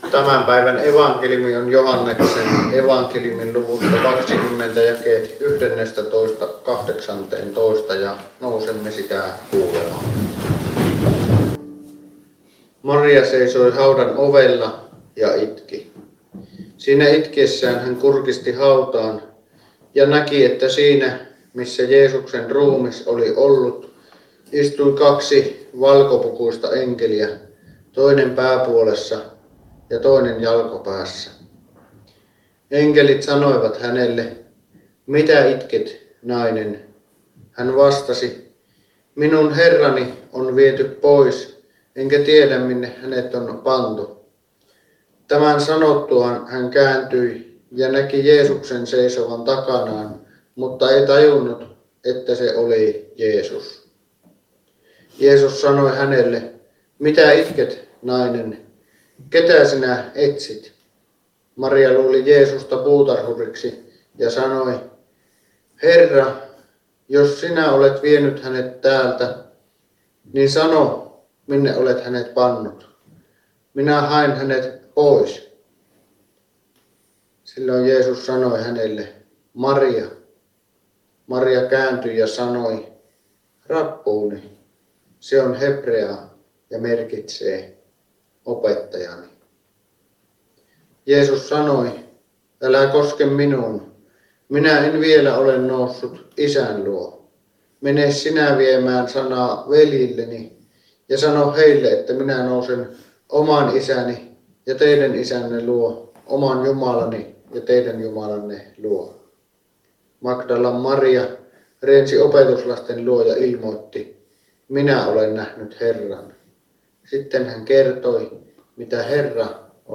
Kälviällä II pääsiäispäivänä Tekstinä Joh. 20:11–18